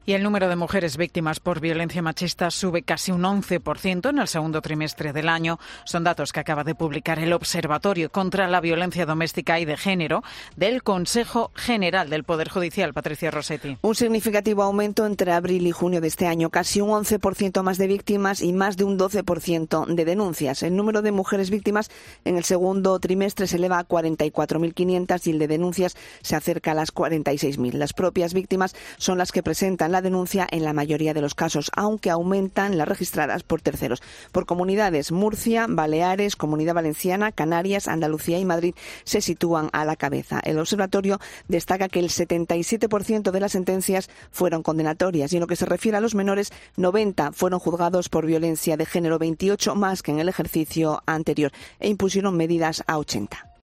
Aumenta el número de mujeres víctimas de violencia machista un 11 %. Crónica